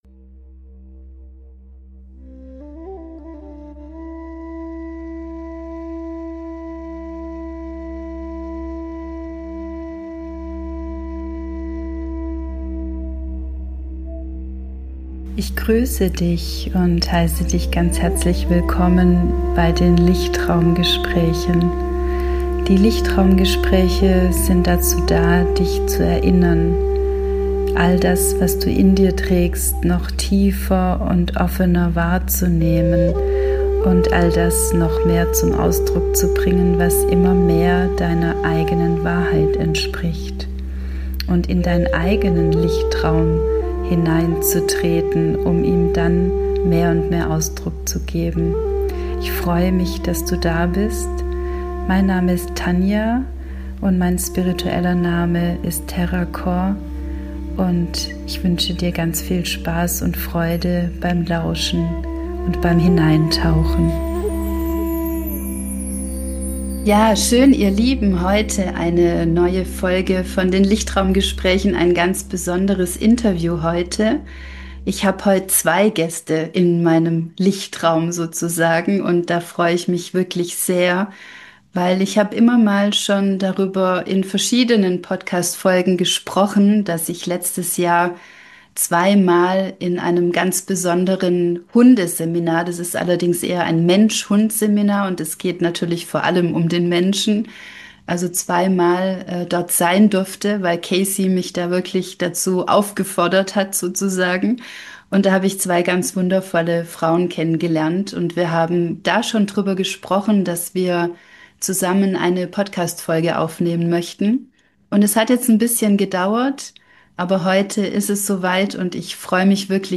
In dieser Folge habe ich zwei tolle Frauen in meinem Podcast als Interview-Partnerinnen.